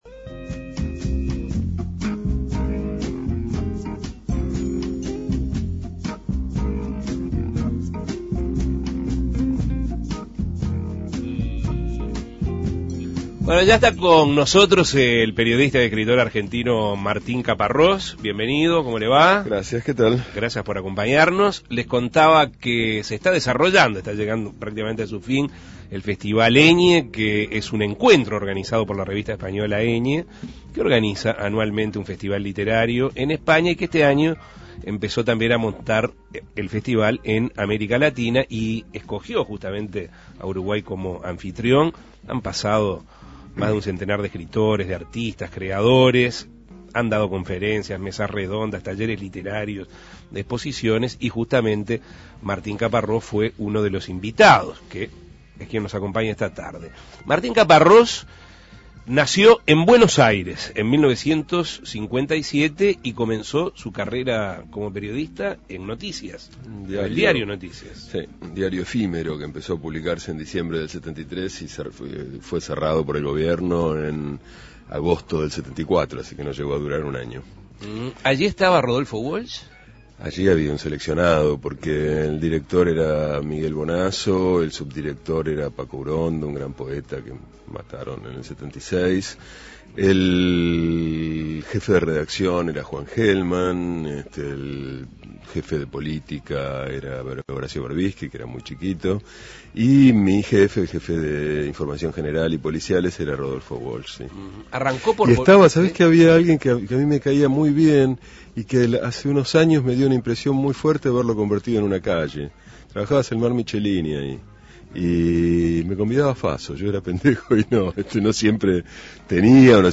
Uno de los invitados es el periodista y escritor argentino Martín Caparrós, que habló con Asuntos Pendientes.